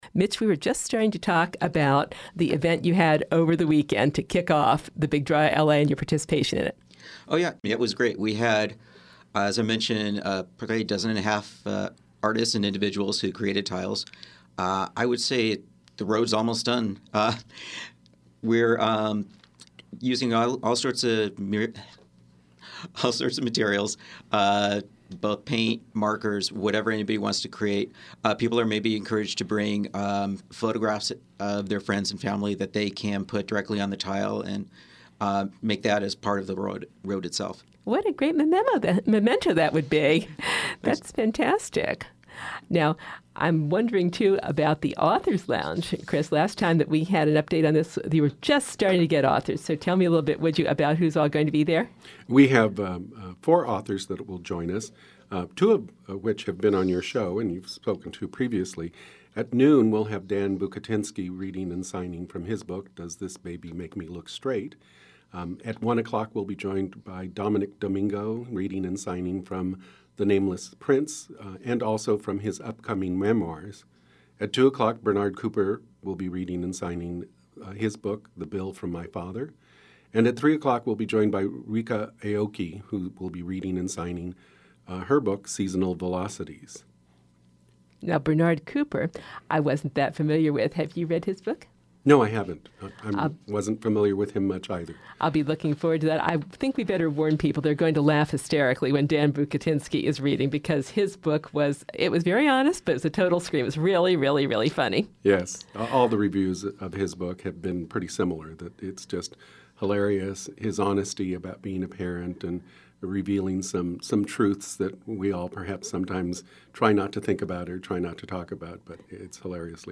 San Gabriel Valley Pride Interview, Part 2